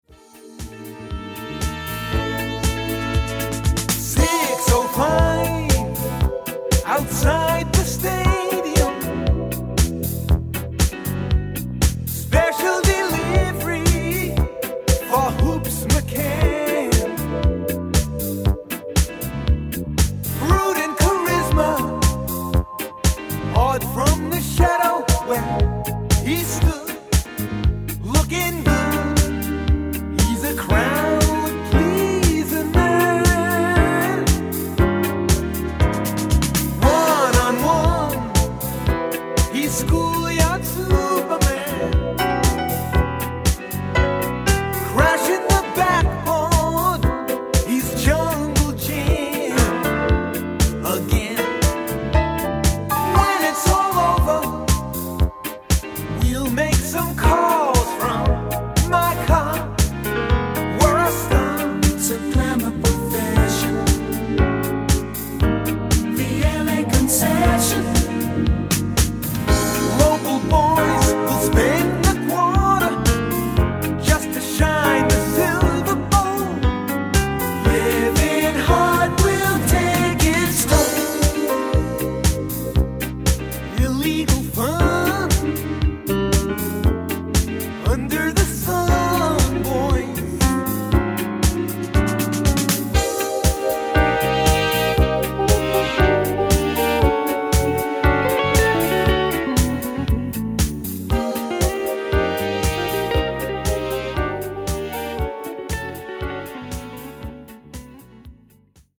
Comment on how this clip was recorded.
It’s an edit I put together with pro-tools.